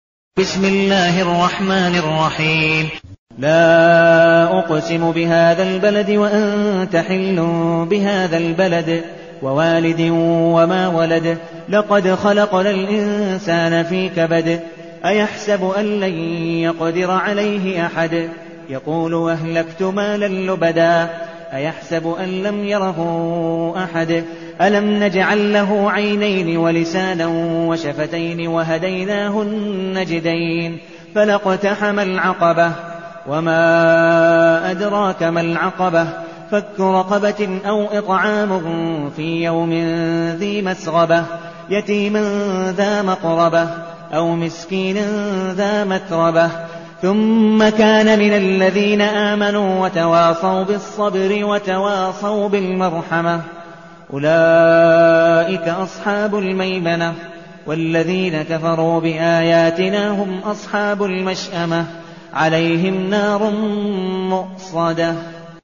المكان: المسجد النبوي الشيخ: عبدالودود بن مقبول حنيف عبدالودود بن مقبول حنيف البلد